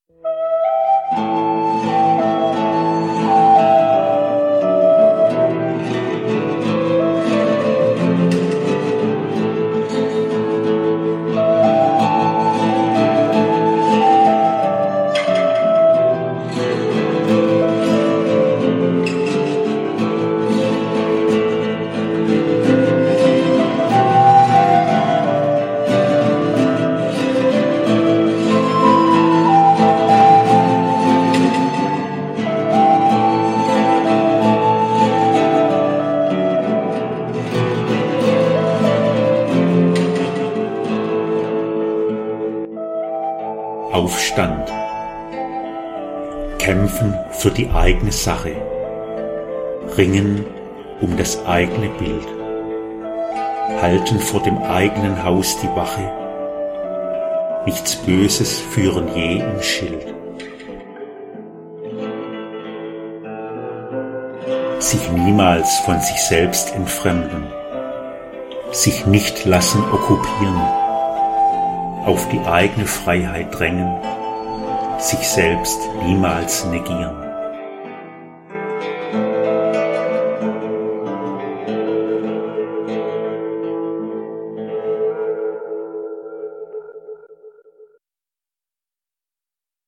Rezitation